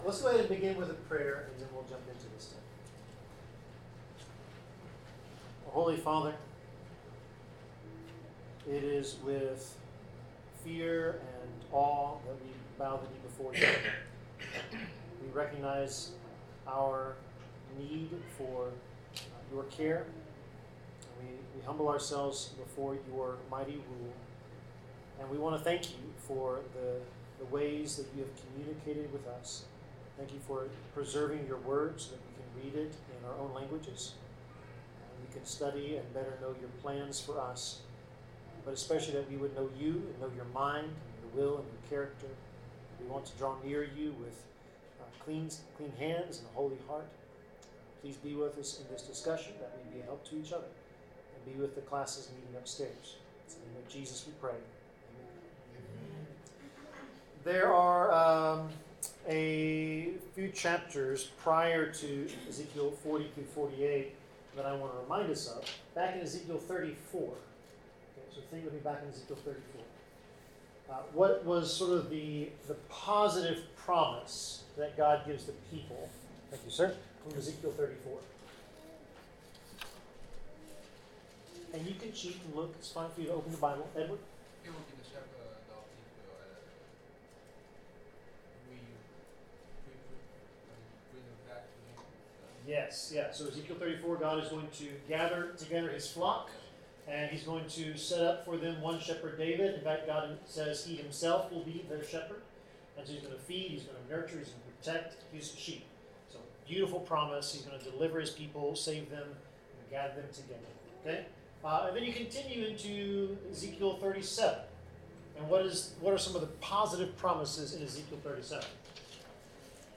Bible class: Ezekiel 40-43
Service Type: Bible Class